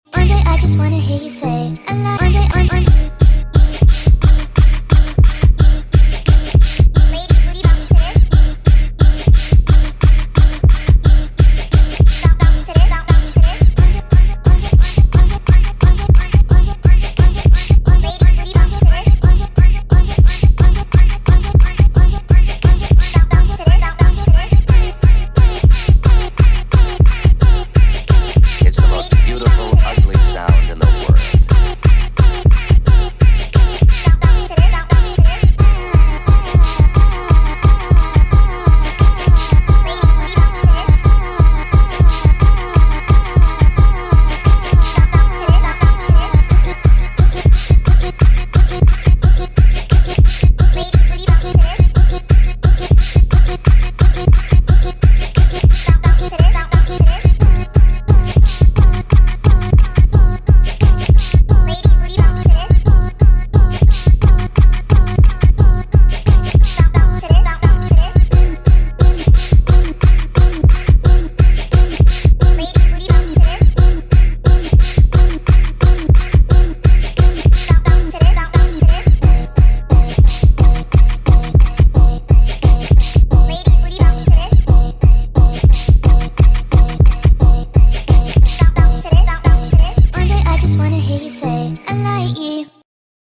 jersey club sped up